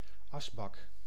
Ääntäminen
Tuntematon aksentti: IPA: /ˈɑzbɑk/